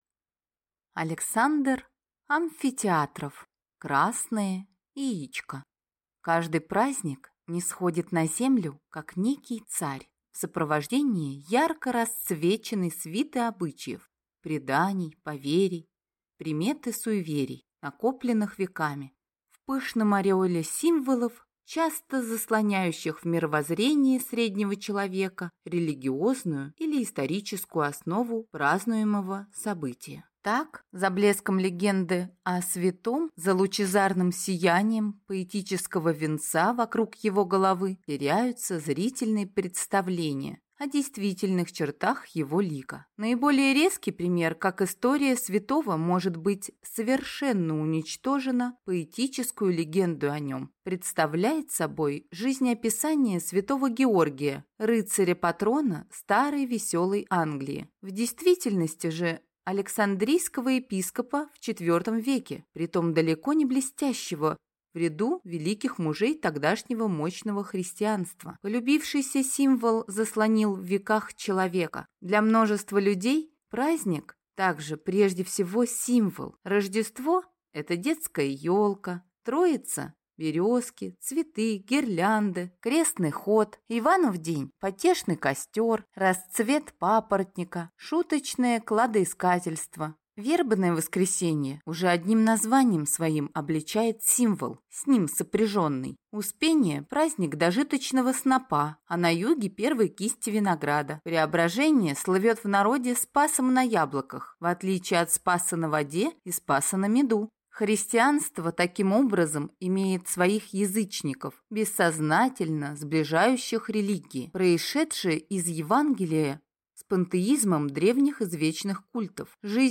Аудиокнига Красное яичко | Библиотека аудиокниг